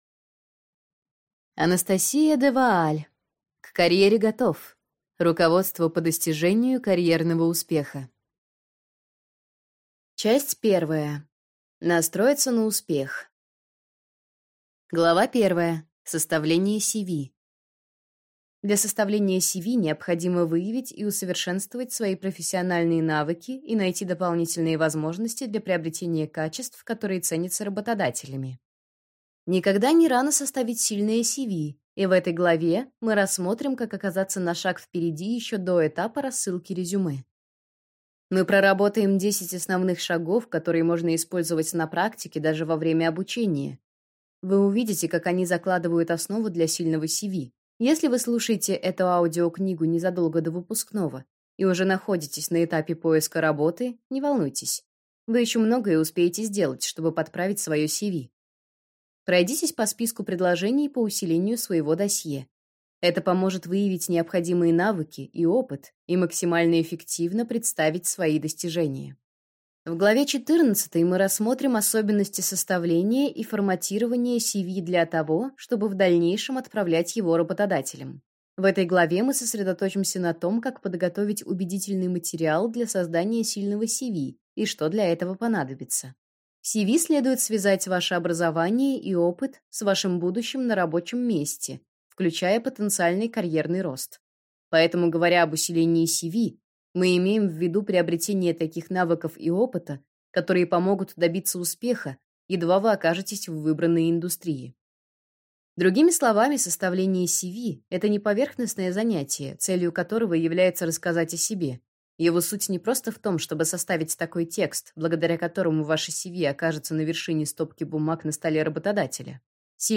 Аудиокнига К карьере готов. Руководство по достижению карьерного успеха | Библиотека аудиокниг